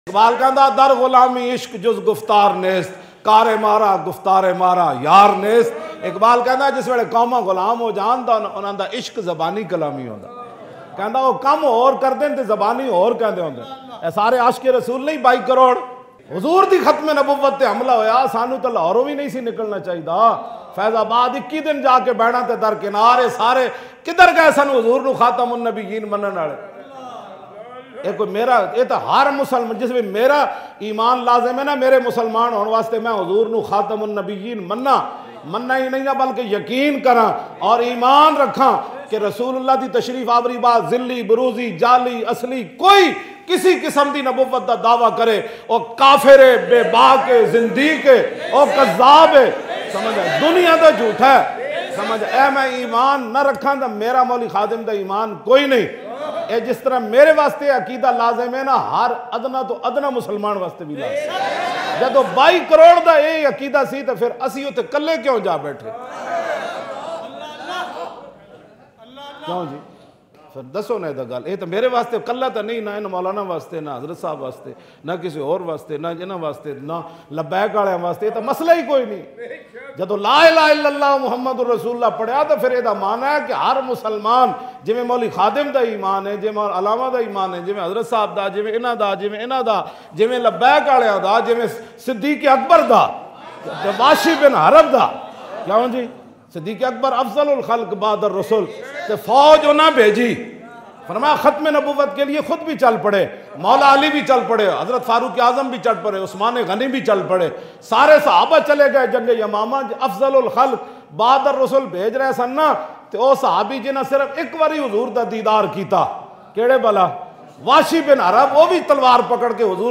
Talking About Coronavirus In China Emotional Allama Khadim Hussain Rizvi Bayan Listen Online & Download MP3.
Talking About Coronavirus In China Allama Khadim Hussain Rizvi Bayan